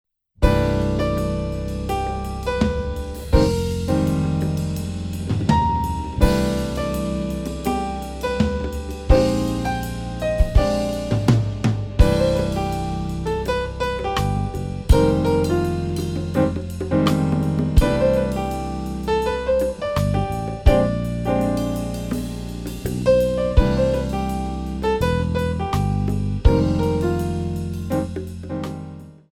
Modern
4 bar intro
cool jazz